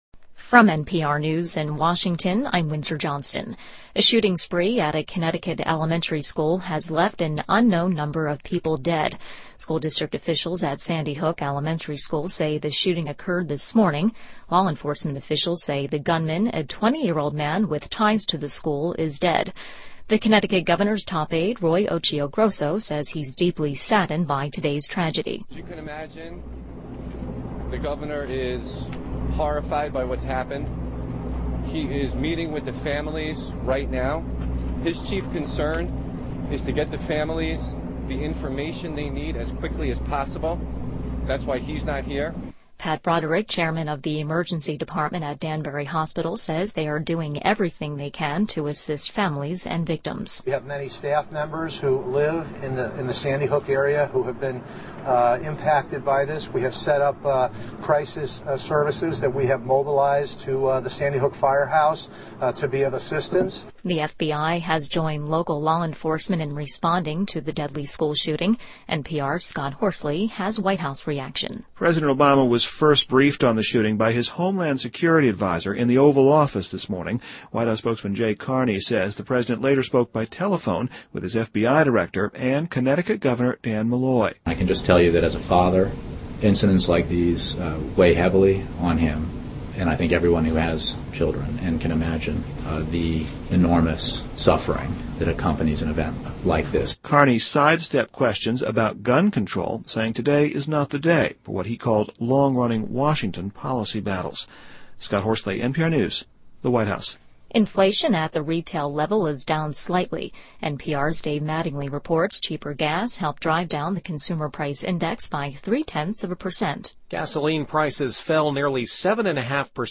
NPR News,2012-12-15